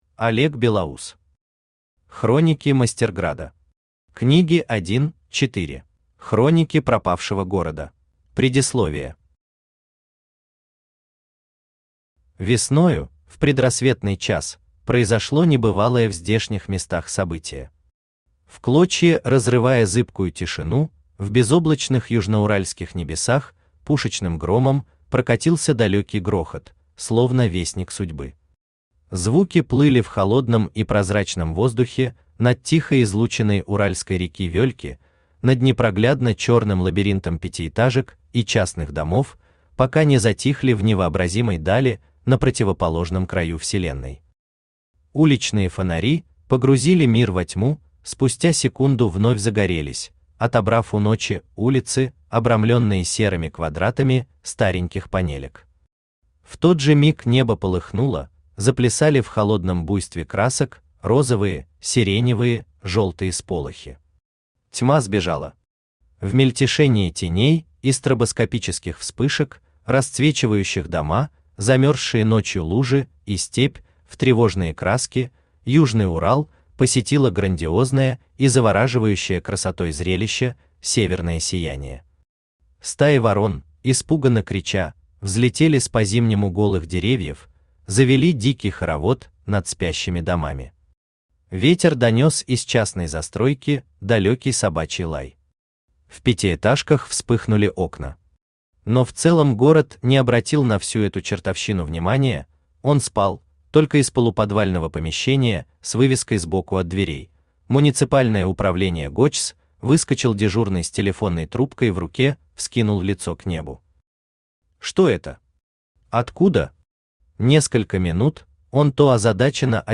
Аудиокнига Хроники Мастерграда. Книги 1-4 | Библиотека аудиокниг
Книги 1-4 Автор Олег Белоус Читает аудиокнигу Авточтец ЛитРес.